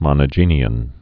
(mŏnə-jēnē-ən)